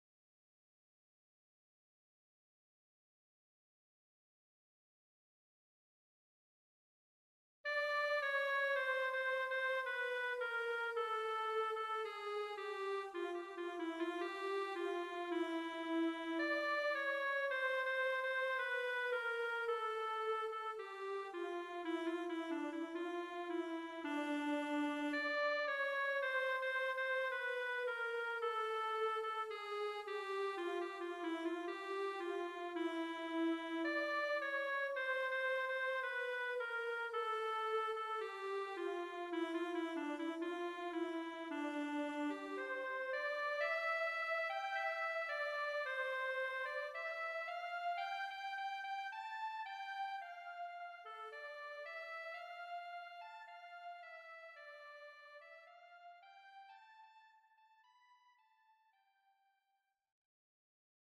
：B-MIDIでメロディをひろい、歌いながら発音の練習をします。
テンポ スロー1
midi_tempo_55.mp3